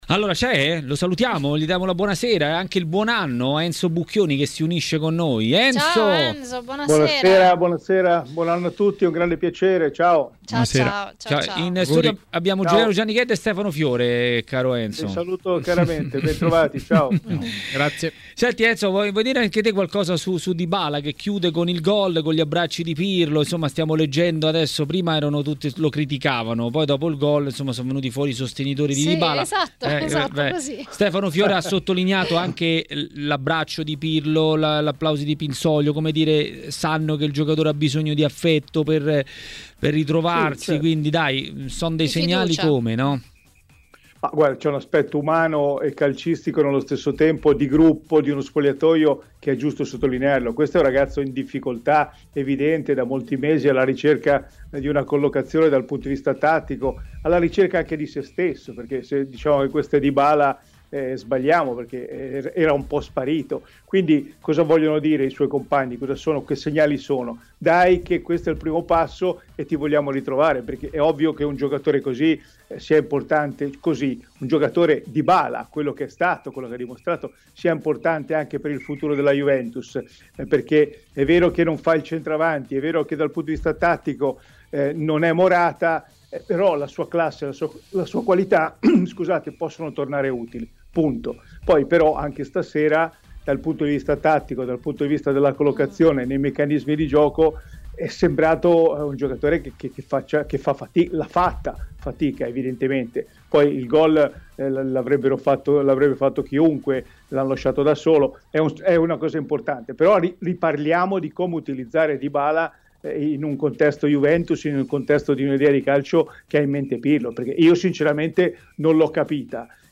Il direttore Mario Sconcerti ha commentato la quindicesima giornata di campionato ai microfoni di Tmw Radio.
Le Interviste